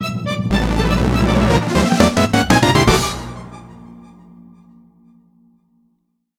The fanfare that plays when starting a multiplayer race